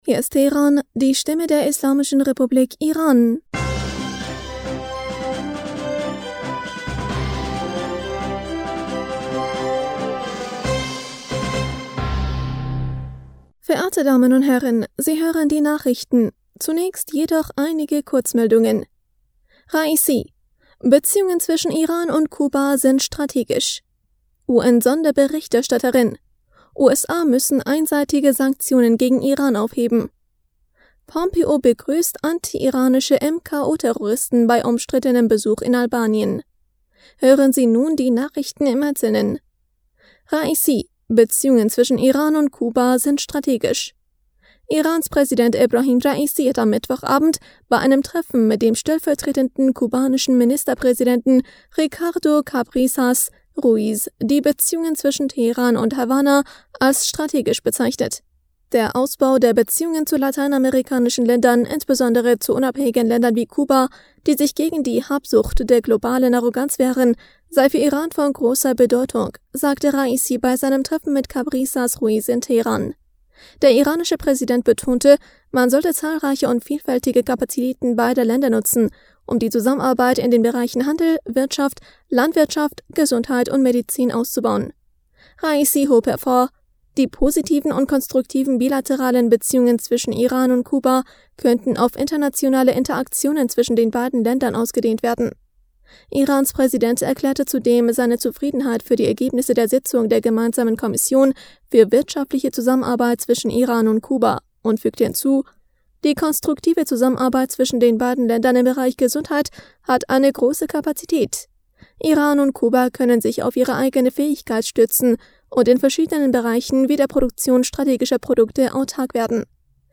Nachrichten vom 19. Mai 2022
Die Nachrichten von Donnerstag, dem 19. Mai 2022